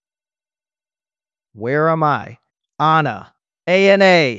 novafarma/assets/audio 🔴/voiceover/prologue/prologue_13.wav